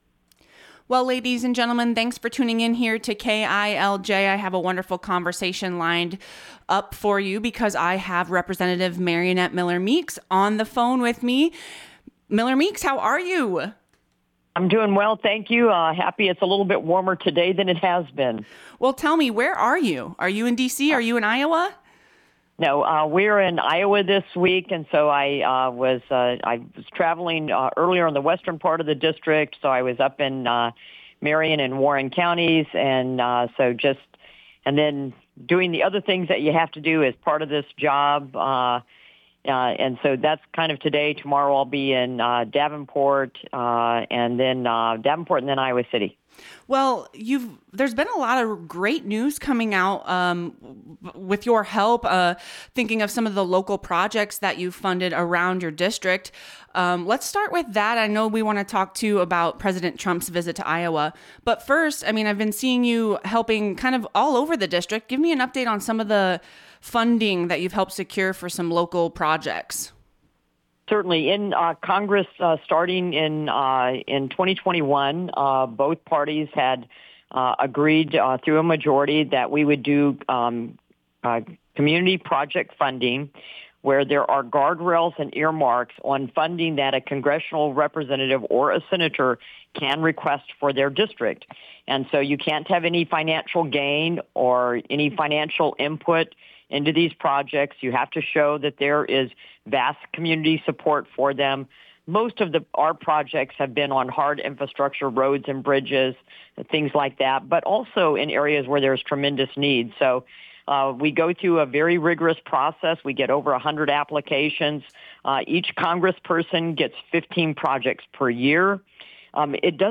Miller-Meeks recently connected with KILJ in an interview focused on current issues in Washington, D.C., and how decisions at the federal level are affecting Iowans in the First Congressional District. Topics discussed included federal priorities, budget and policy debates, and what they could mean for communities and families across southeast Iowa.